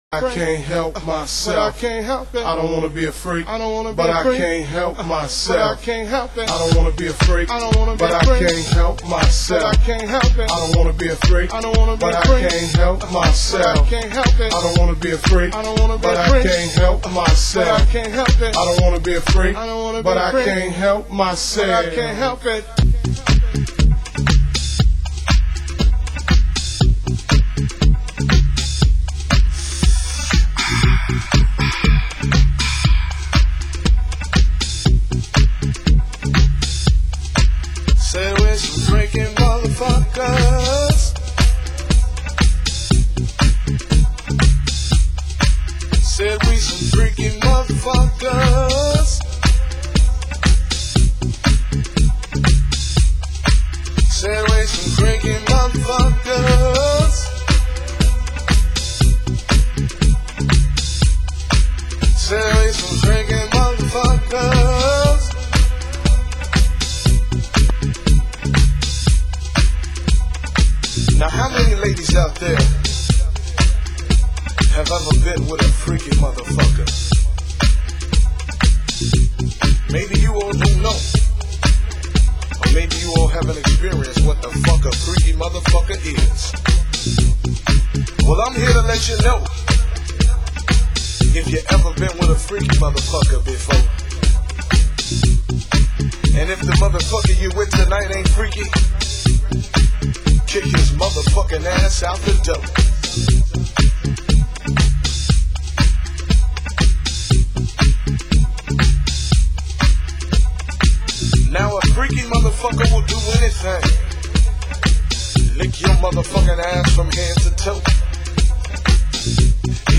Genre: Chicago House